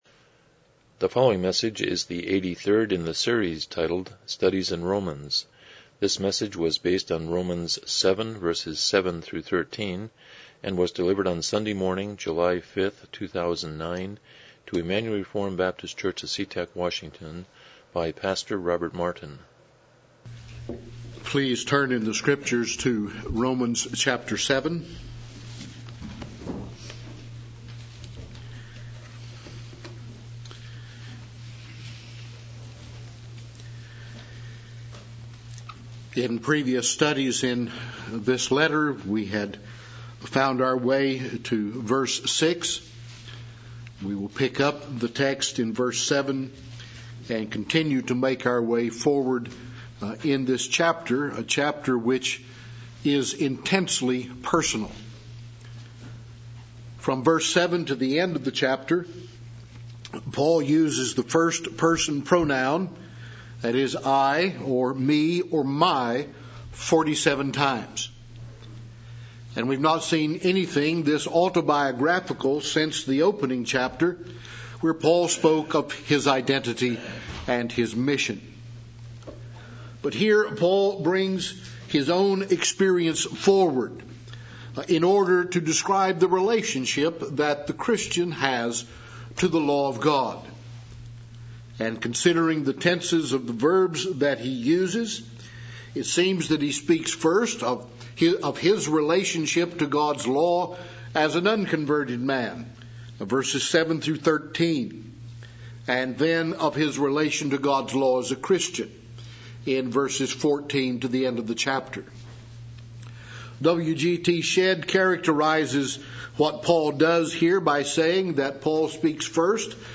Exposition of Romans Passage: Romans 7:7-13 Service Type: Morning Worship « 37 What is a Covenant?